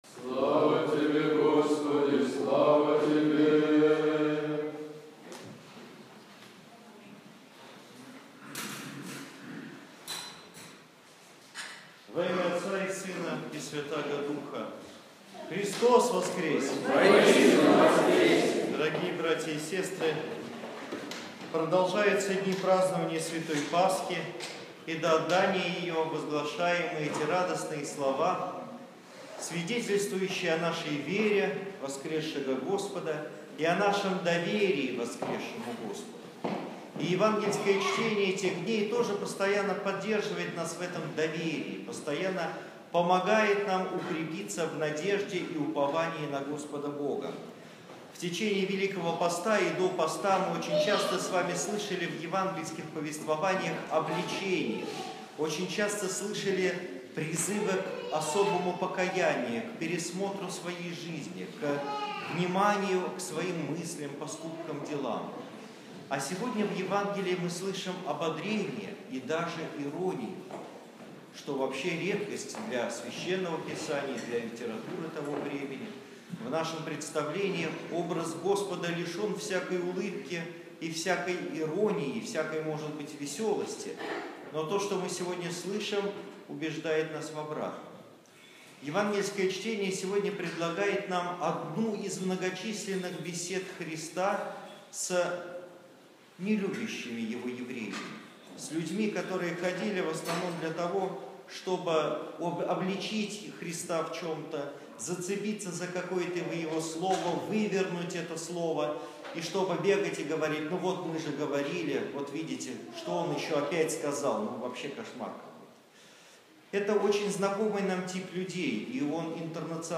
16 мая 2015 года. Суббота, память прп. Феодосия Киево-Печерского. Проповедь на литургии